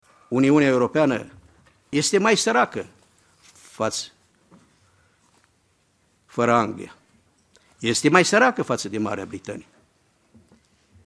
Ministrul Agriculturii, Petre Daea, a prezentat astăzi în Comisia de Pescuit a Parlamentului European prioritățile președinției României.
Petre Daea a luat cuvântul la Bruxelles și a prezentat modul în care România vrea sa contribuie la dezvoltarea Uniunii Europene. Ministrul a ajuns și la concluzia că ”Uniunea Europeană este mai săracă fără Anglia”: